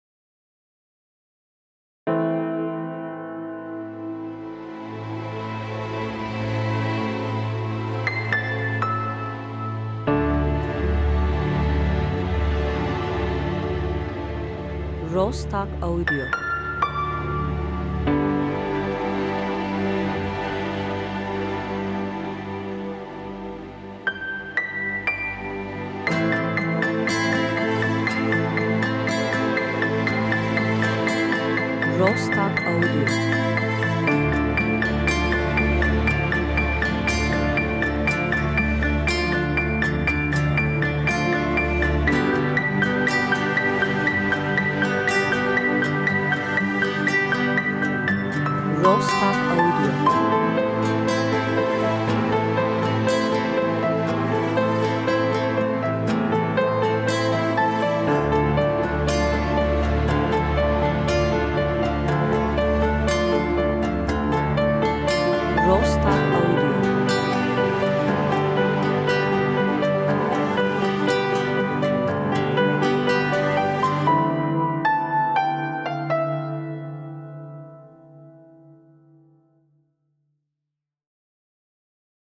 enstrümantal ses müzik duygusal fon epic epik